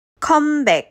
カムバの発音
「カムバ」は「カムバック(カンバツク)」ですが、comebackを韓国語で「컴백」と書きます。日本人には「コムベッ」のように聞こえますが、韓国人にはcomebackです。